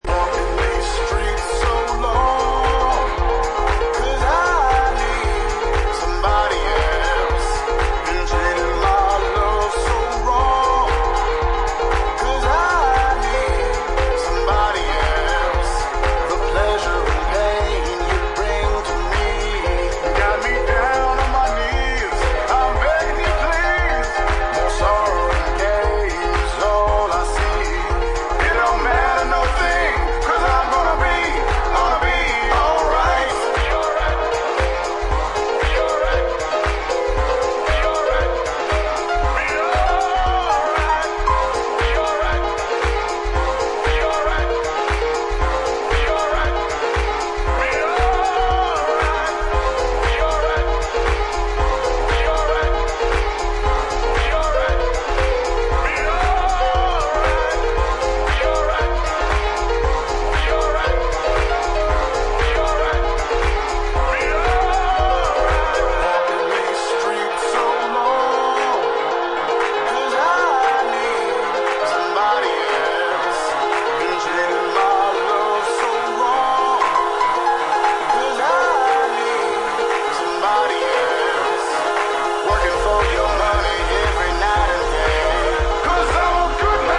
HOUSE/BROKEN BEAT